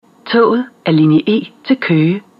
efter opdatering af togets computer 2012 , 4. generations S-tog